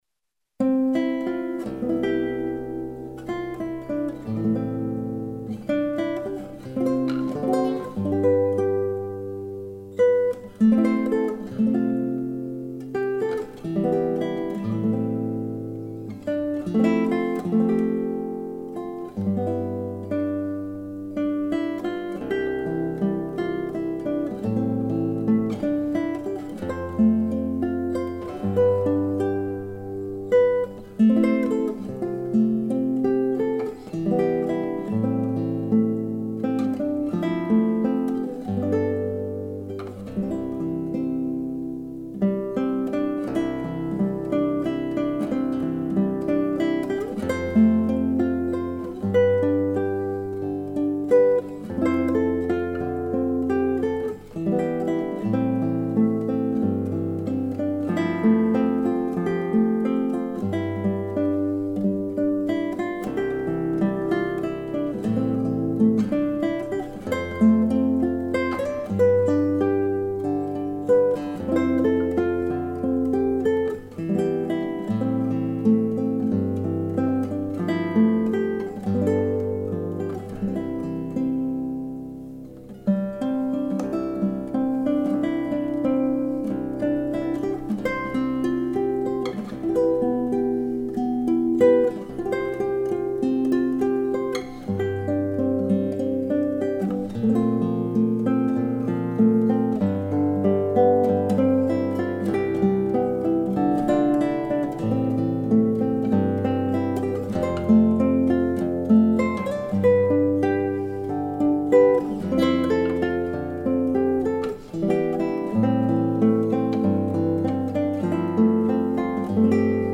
Early American, Solo Guitar
DIGITAL SHEET MUSIC - FINGERSTYLE GUITAR SOLO